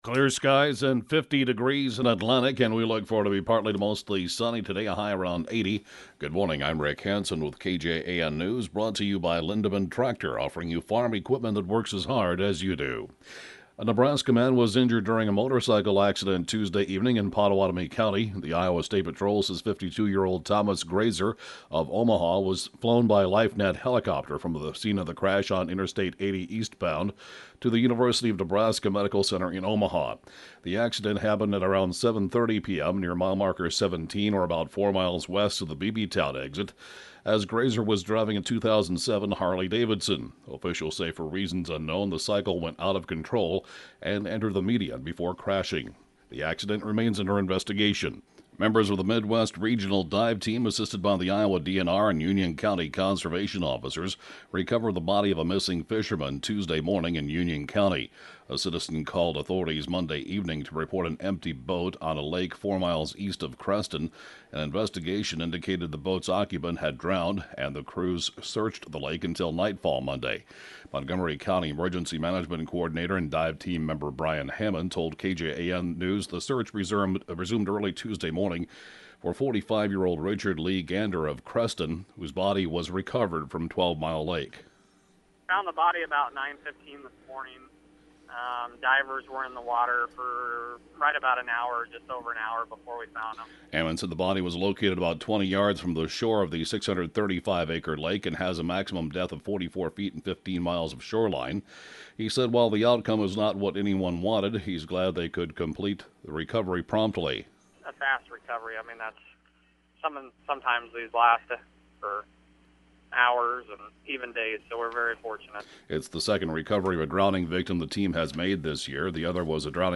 (Podcast) 7:07-a.m. News/Funeral report, Wed. 7/30/2014